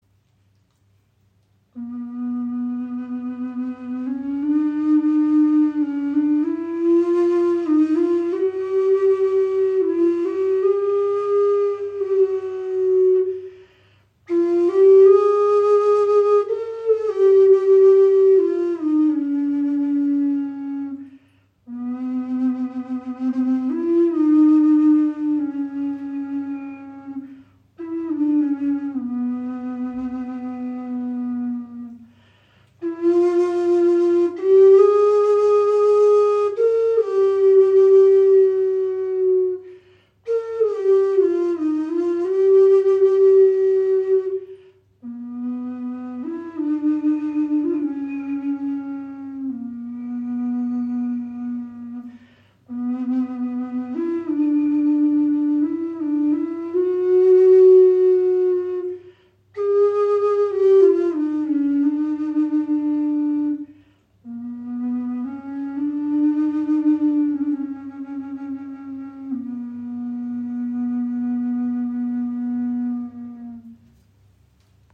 Okarina aus einem Aststück | C4 in 432 Hz | Aeolian Stimmung | ca. 27 cm
Handgefertigte 6 Loch Okarina aus Akazienast – klarer, warmer Klang in Aeolischer Stimmung in C (432 Hz), jedes Stück ein Unikat.
Die Okarina spricht besonders klar an und erklingt in der Aeolischen Stimmung in C4, fein abgestimmt auf 432 Hz – ein Ton, der Herz und Geist in harmonische Schwingung versetzt.
Trotz ihrer handlichen Grösse erzeugt sie einen angenehm tiefen, warmen Klang – fast ebenbürtig zur nordamerikanischen Gebetsflöte.